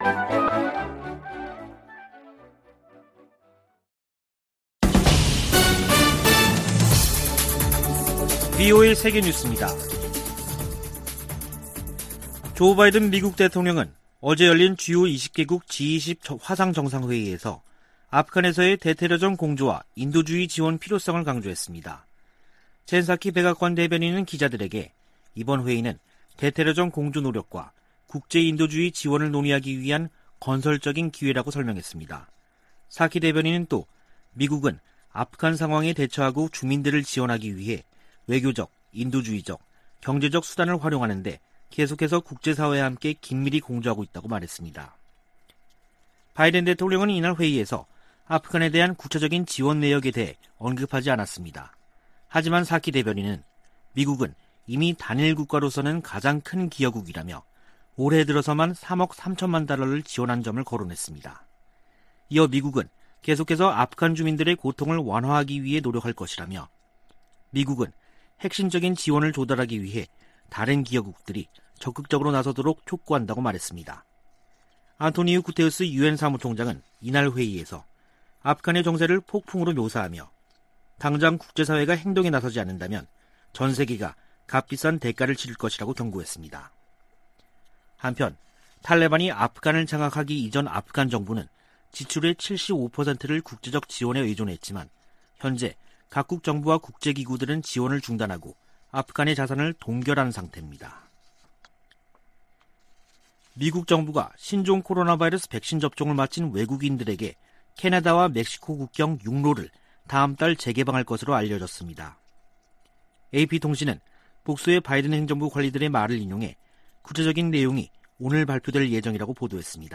VOA 한국어 간판 뉴스 프로그램 '뉴스 투데이', 2021년 10월 13일 3부 방송입니다. 미국이 적대적이지 않다고 믿을 근거가 없다는 김정은 북한 국무위원장 발언에 대해, 미 국무부는 적대 의도가 없다고 강조했습니다. 제이크 설리번 미 국가안보좌관이 워싱턴에서 서훈 한국 국가안보실장과 북한 문제 등을 논의했습니다. 유엔 안보리 대북제재위원회로부터 제재 면제를 승인 받은 국제 지원 물품들이 북한 반입을 위해 대기 상태입니다.